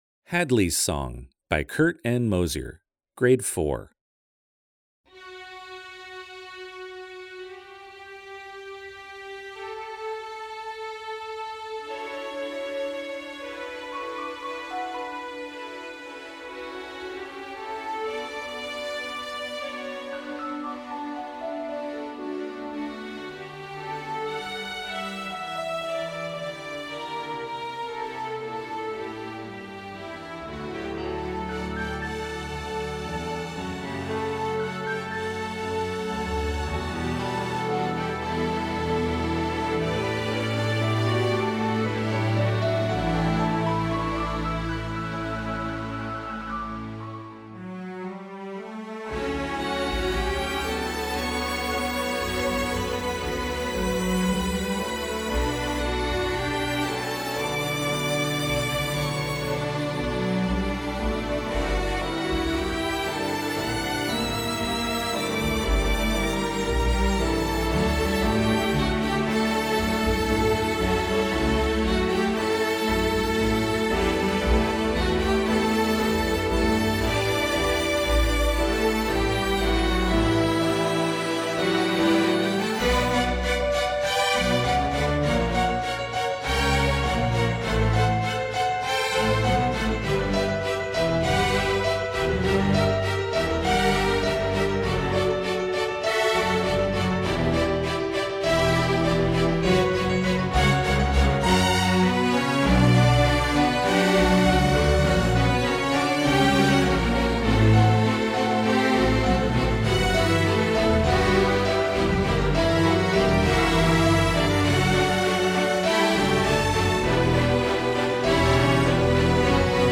String Orchestra (M)   Score